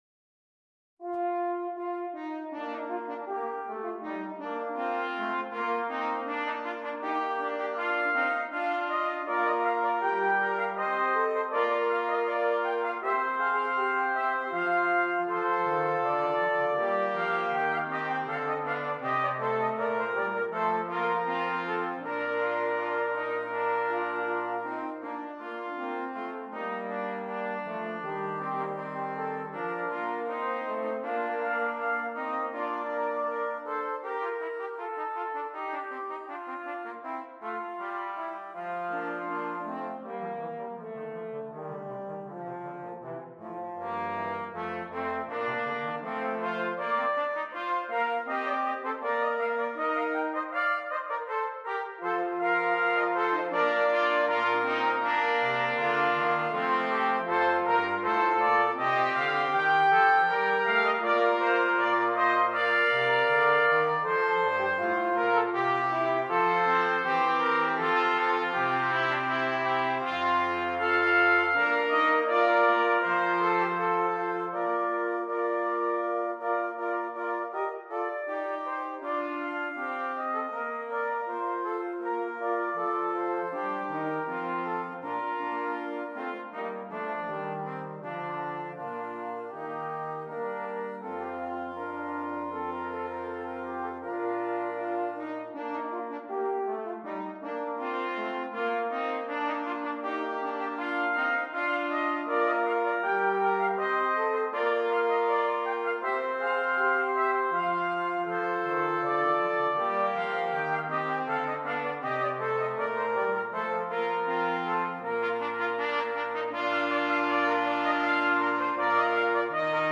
• Brass Quartet